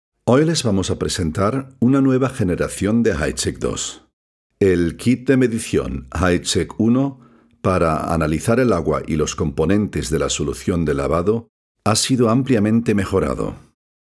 Zwischen Spanien und Deutschland zweisprachig hin und her pendelnd lernte er 2 Sprachen akzentfrei zu sprechen.
Sprechprobe: eLearning (Muttersprache):